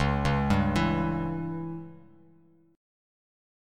C#dim chord